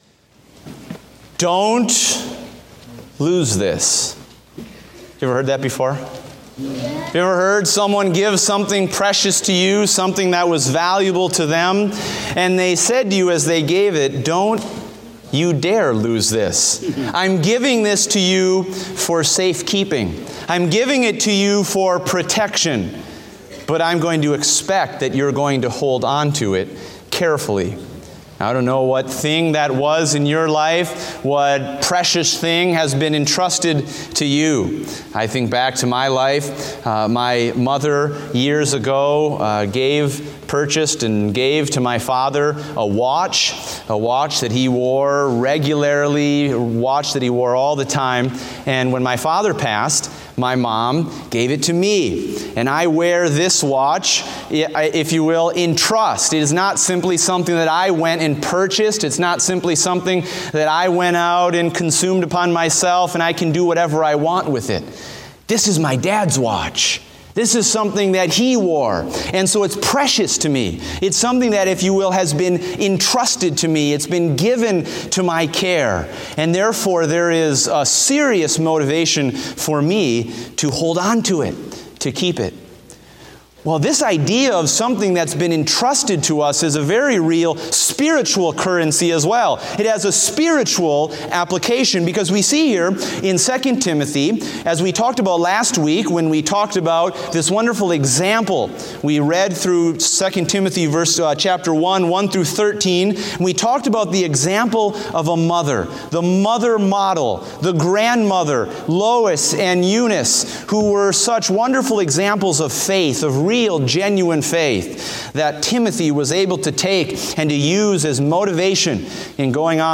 Date: May 15, 2016 (Morning Service)